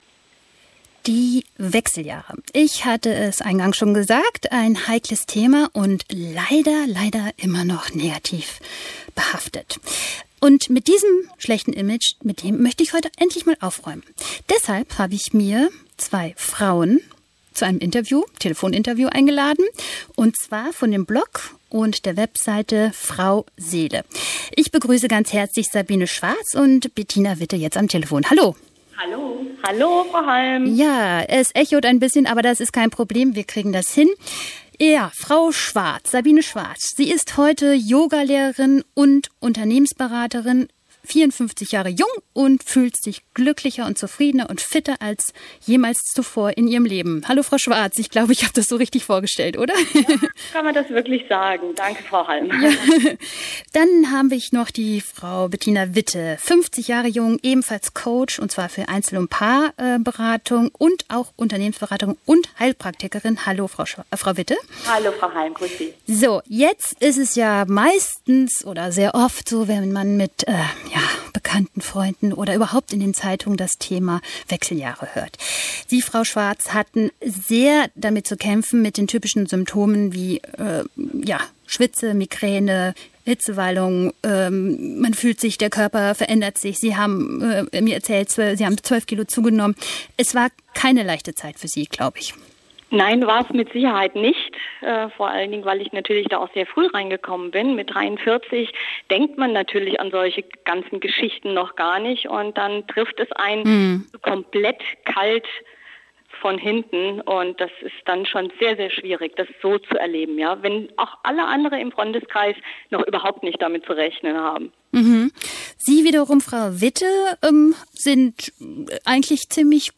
Gedanken über die Wechseljahre – das Interview
Es war unsere erste Radio-Interview-Erfahrung und dementsprechend waren wir auch etwas aufgeregt.